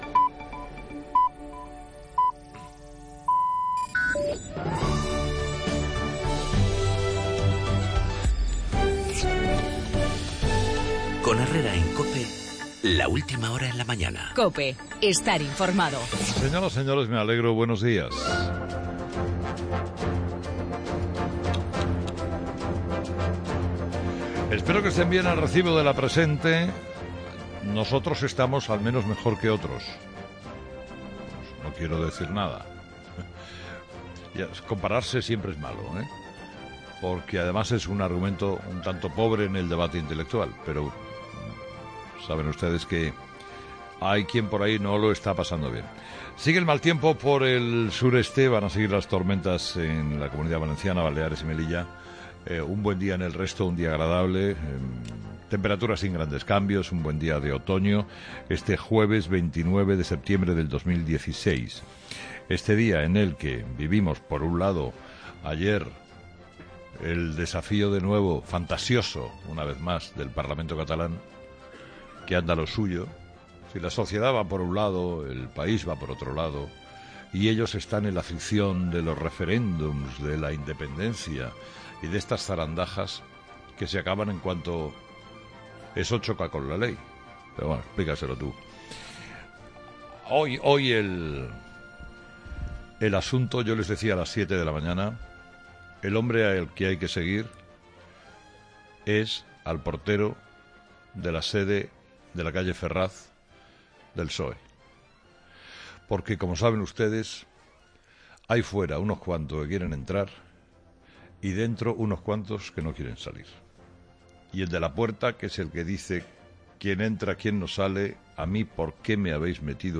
Herrera en la Fundación Telefónica